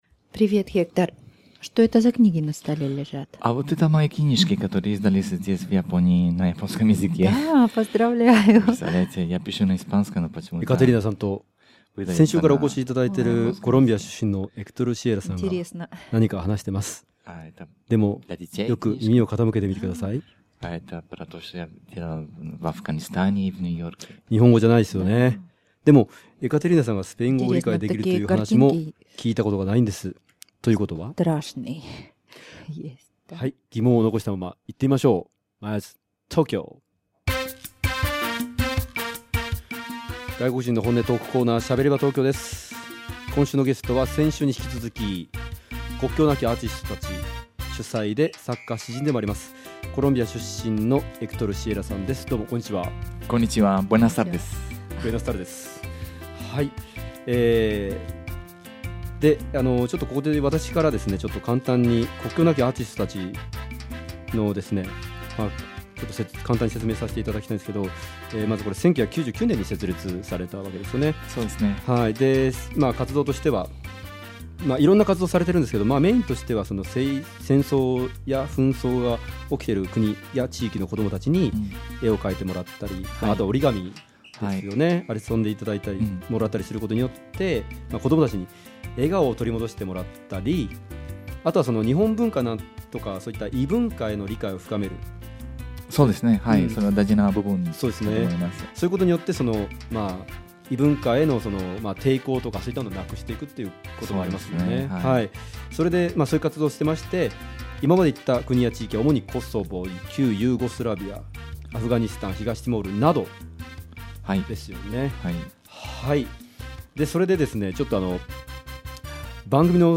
Neither Japanese nor Spanish. They were talking to each other in Russian.